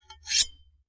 Metal_84.wav